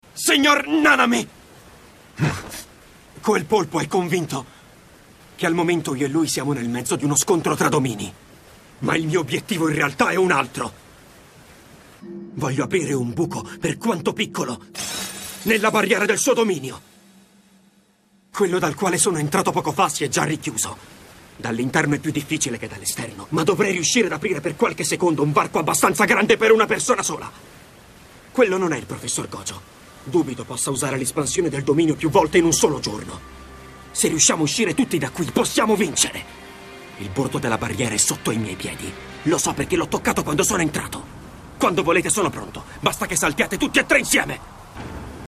nel cartone animato "Jujutsu Kaisen", in cui doppia Megumi Fushiguro.